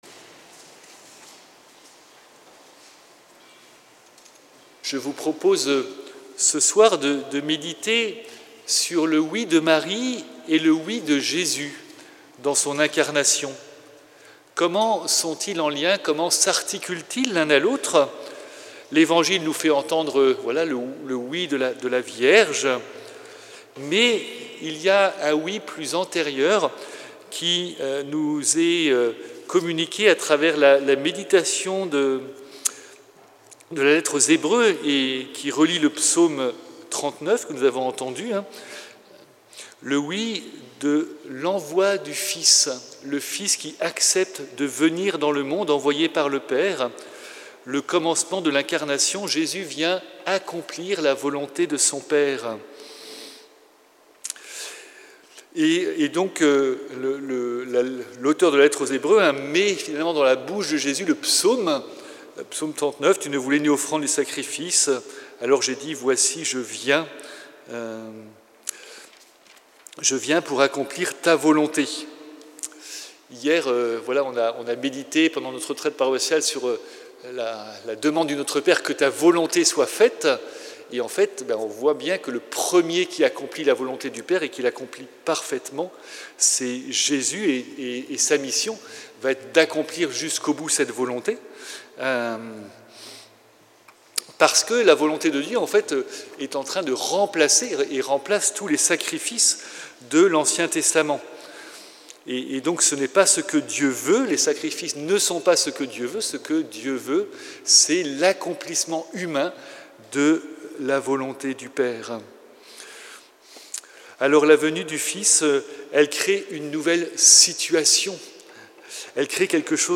Audio : Homélie de l’Annonciation 25 mars 2026